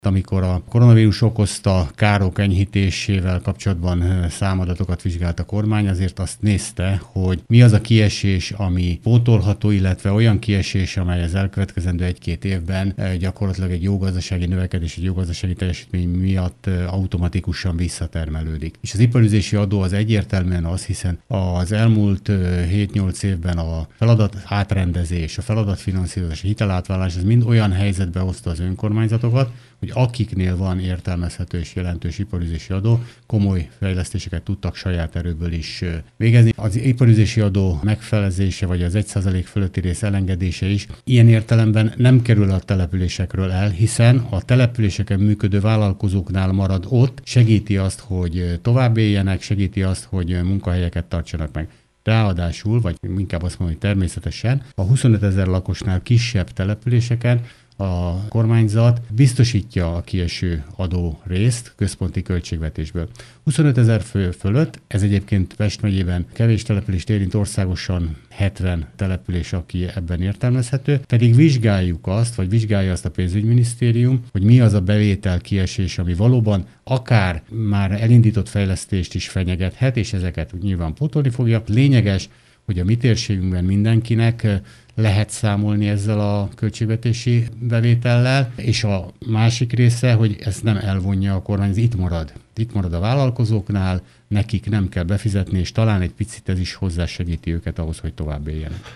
Központi költségvetési támogatást kapnak a 25 ezer fő alatti települések a kieső iparűzési adó kompenzálására. A kormány másfél hónappal ezelőtt döntött arról, hogy a kkv-k számára elengedik az iparűzési adó felét, ami fontos bevételi forrása az önkormányzatoknak. Pogácsás Tibor országgyűlési képviselő, a Belügyminisztérium önkormányzatokért felelős államtitkára volt rádiónk vendége.